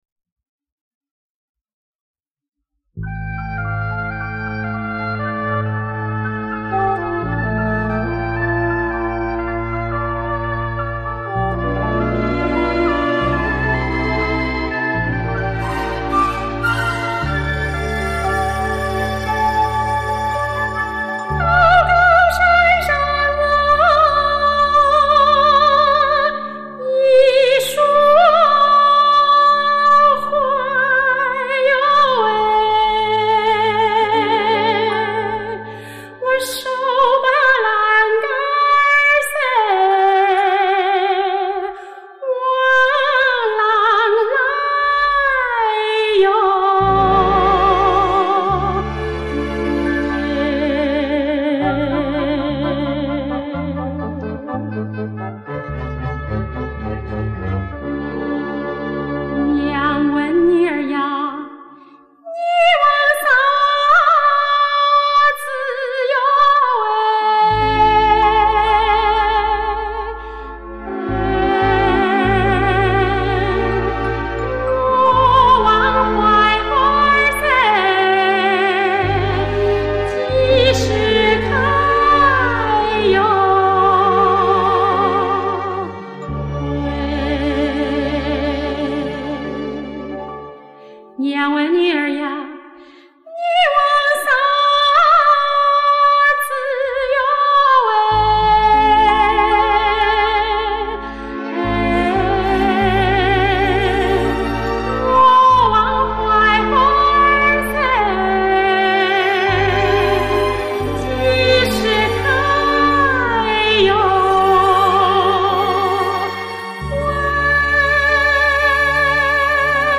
四川民歌
南坪民歌